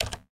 terminal_button07.ogg